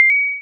bling4.mp3